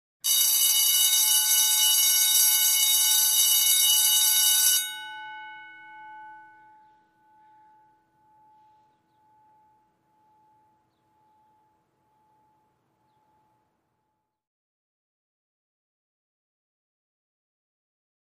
School Bell; Long Ring, Exterior, Close Perspective.